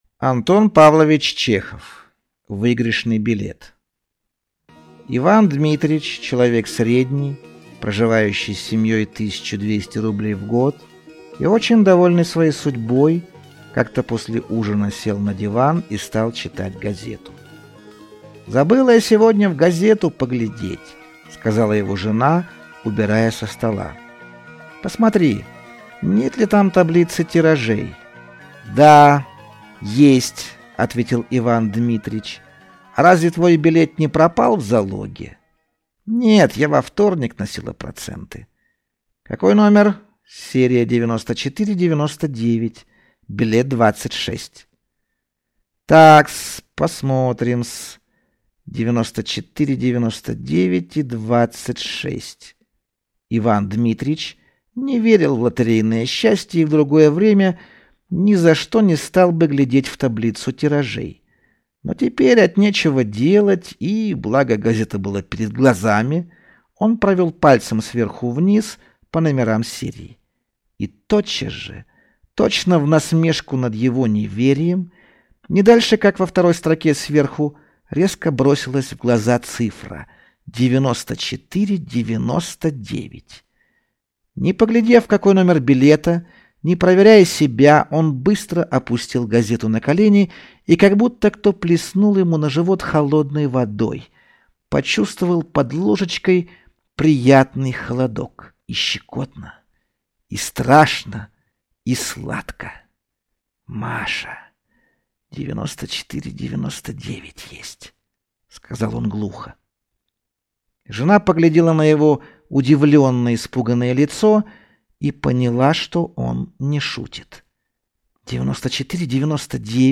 Аудиокнига Выигрышный билет | Библиотека аудиокниг